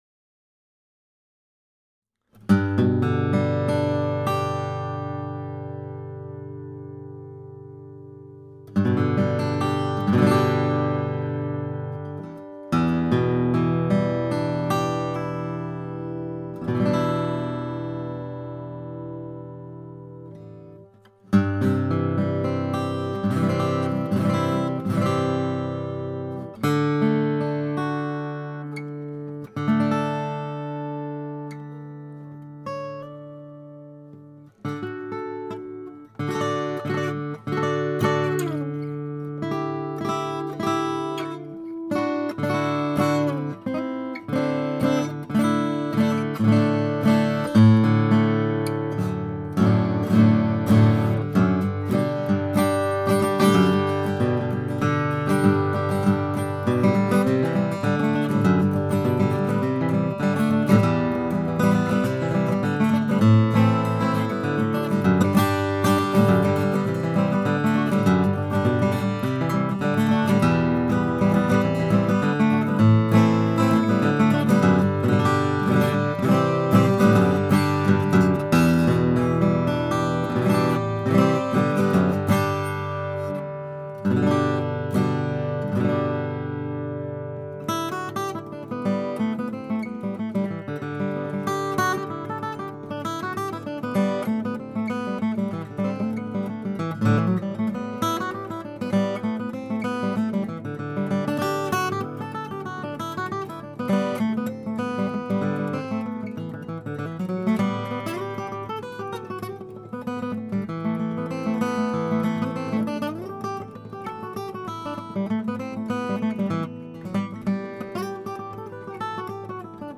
Many of these were made here in the shop about as simply as they could be done.
A classic OM in rosewood and red spruce.